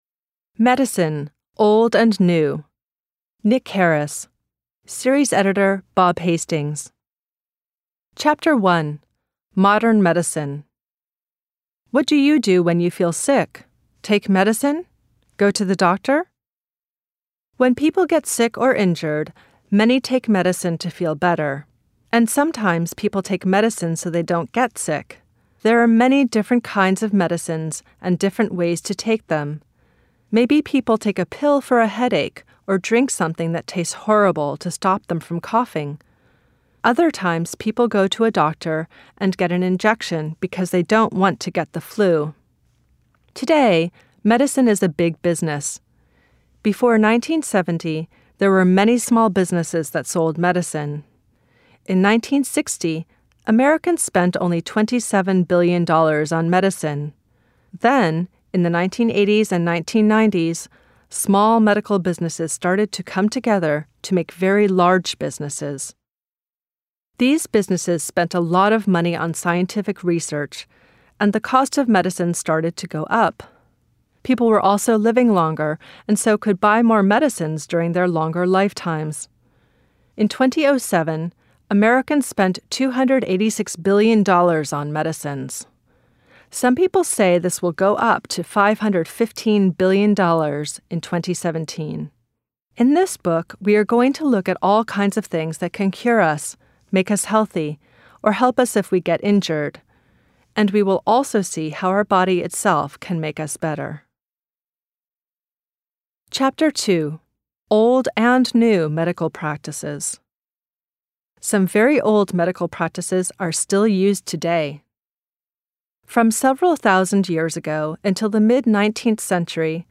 Graded readers (short stories)